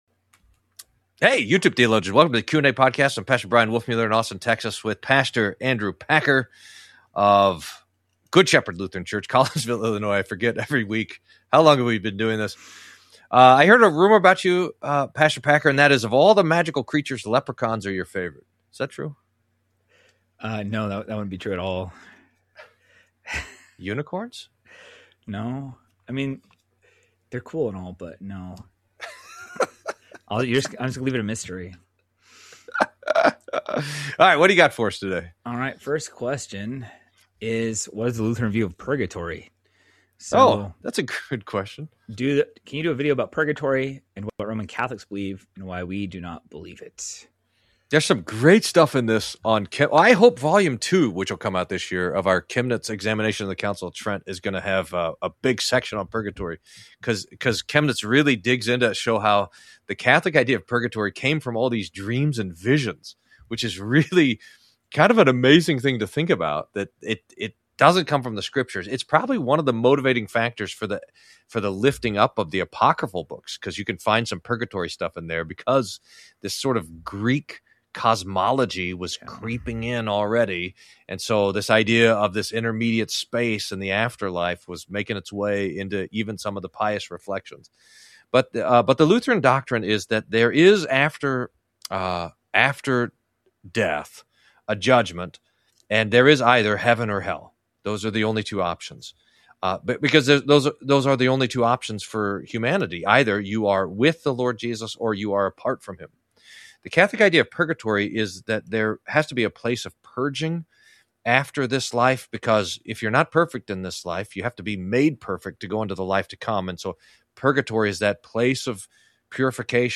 Q&A: Why don't Lutherans believe in purgatory? How should the Church speak out? Living together without intimacy? More.